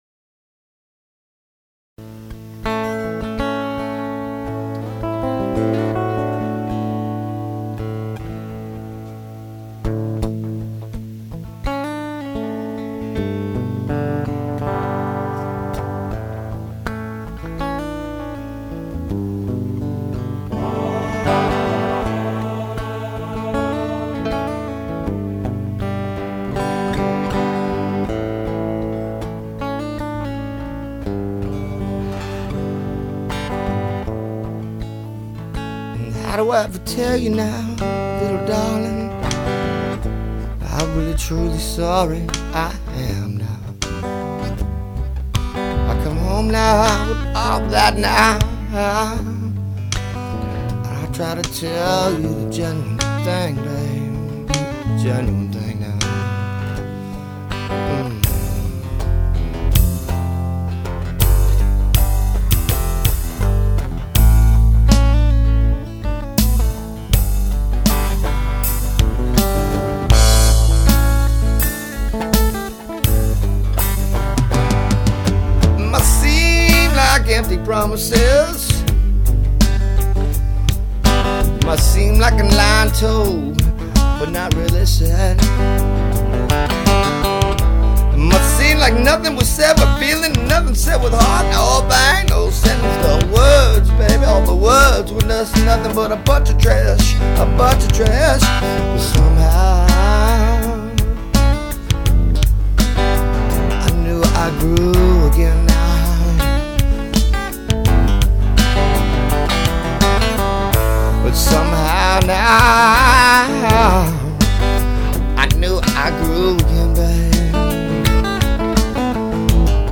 This is the Ballad part 2 of a 2 song set.